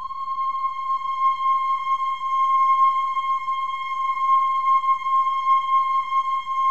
OH-AH  C6 -R.wav